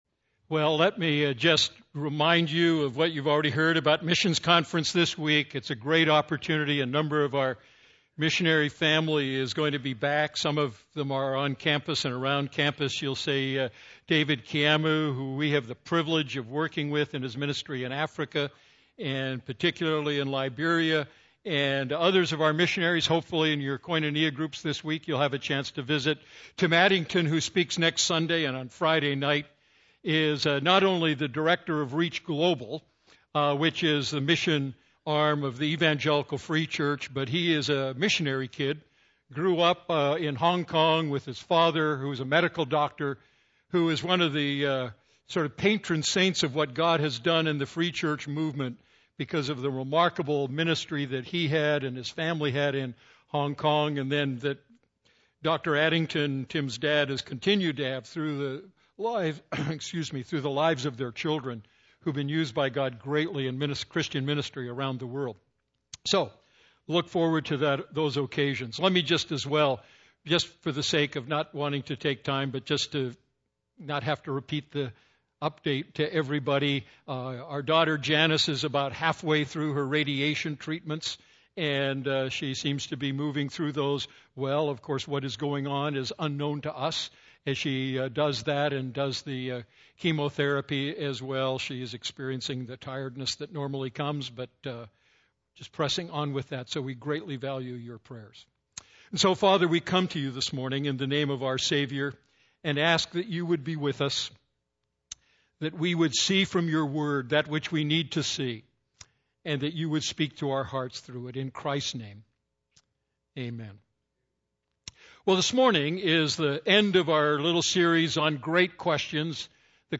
A message from the series "Great Questions?."